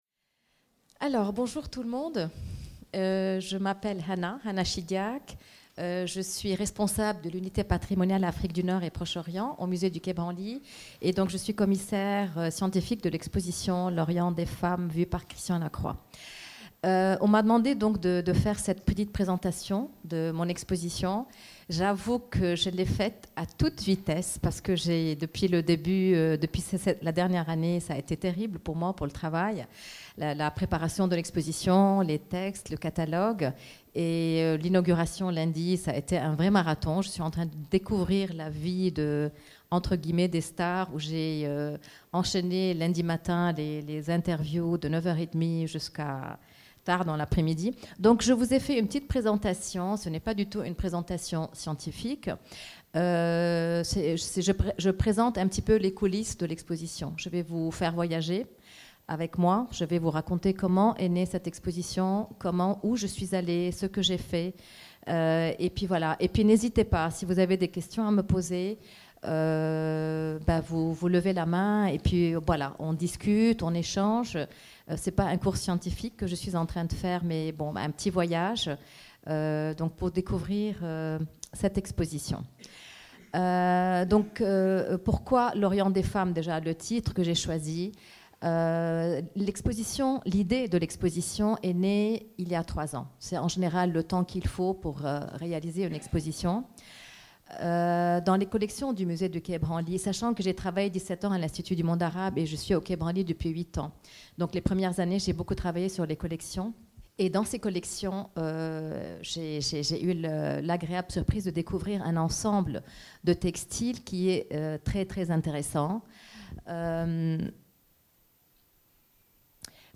Conférence
Salon de lecture Jacques Kerchache